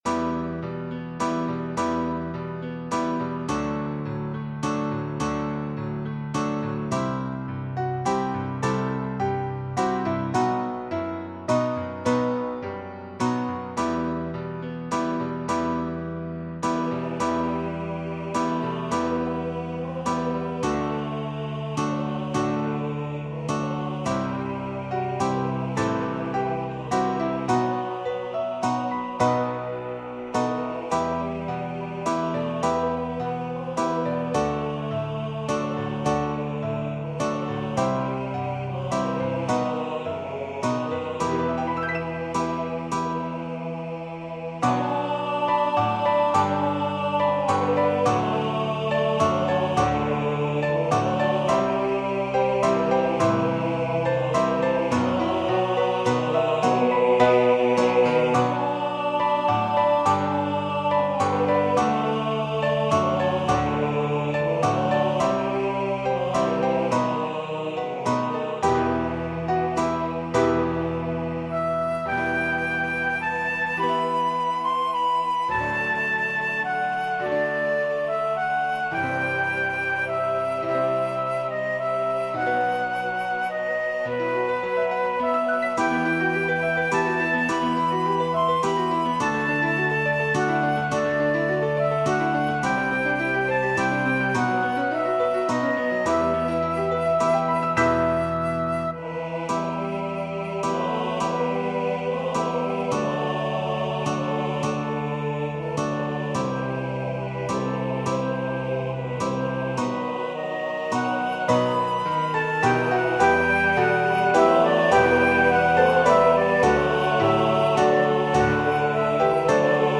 2 part choir, Flute Solo, Guitar, Piano Solo
Voicing/Instrumentation: 2 part choir , Flute Solo , Guitar , Piano Solo We also have other 55 arrangements of " What Child Is This ".